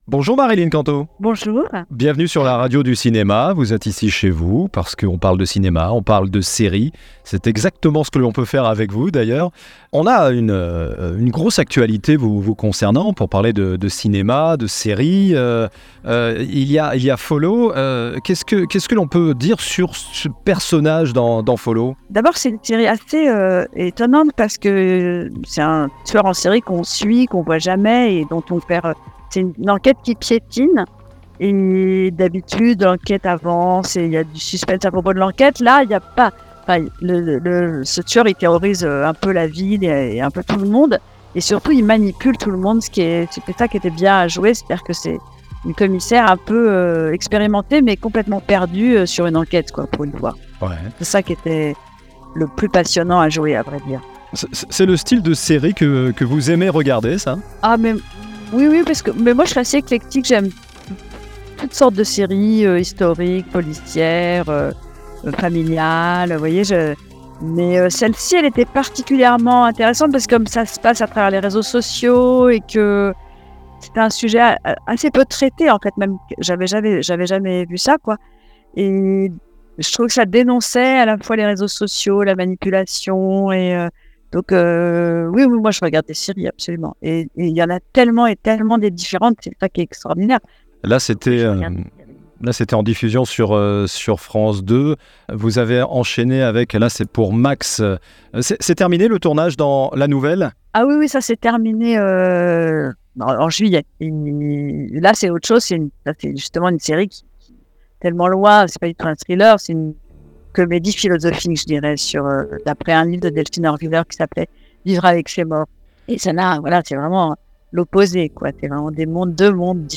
Lors de cette interview, Marilyne a également révélé son amour pour la musique de films, citant des compositeurs légendaires comme Ennio Morricone et Henry Mancini (extraits dans le podcast)